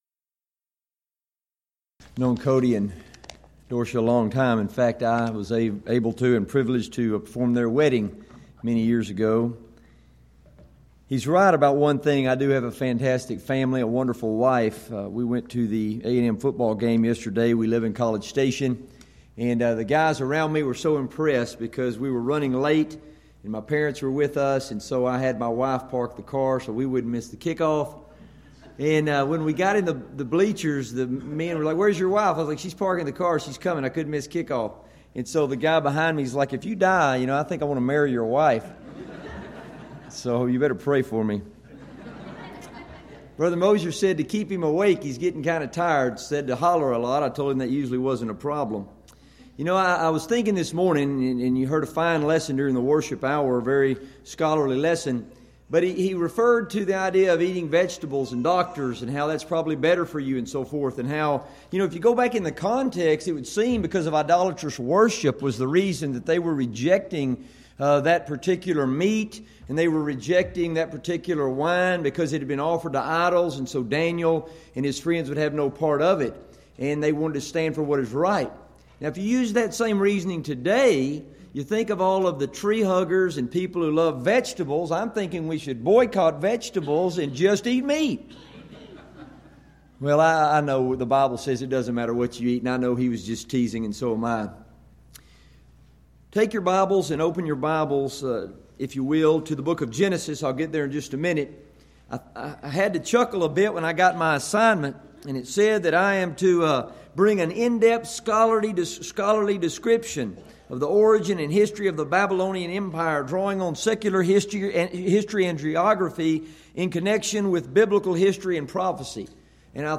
Event: 11th Annual Schertz Lectures Theme/Title: Studies in Daniel